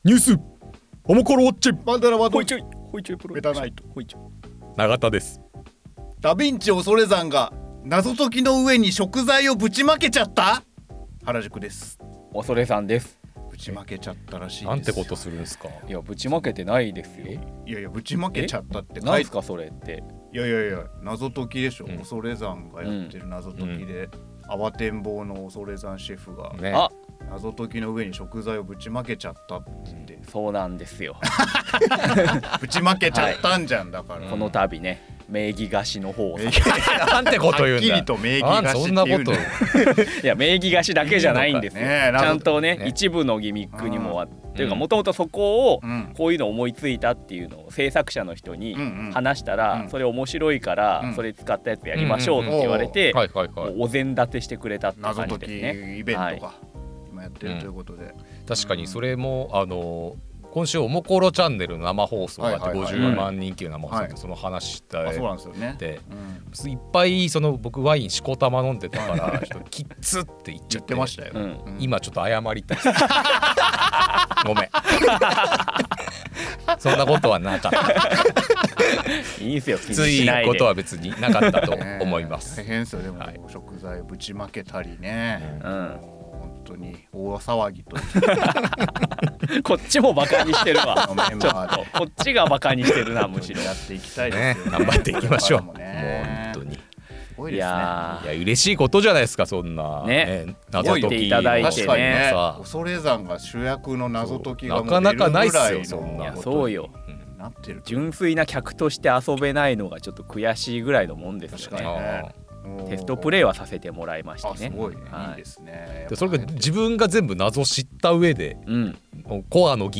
オモコロ編集部の3人が気になるニュースについて語ります。